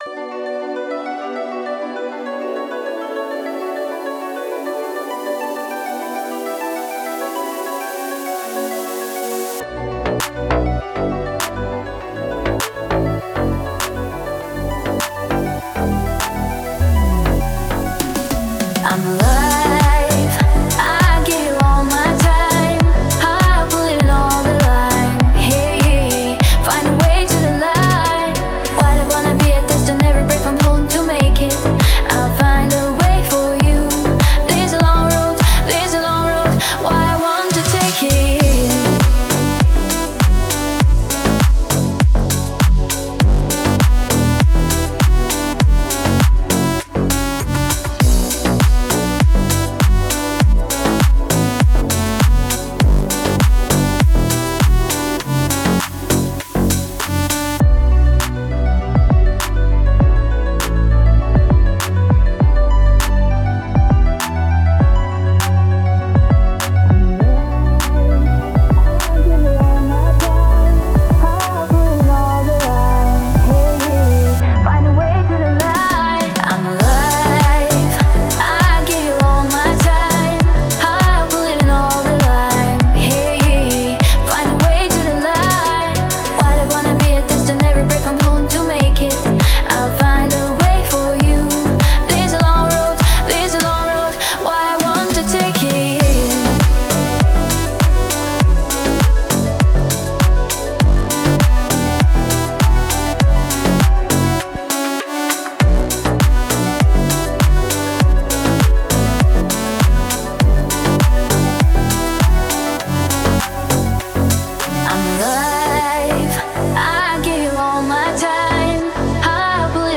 ремейки песен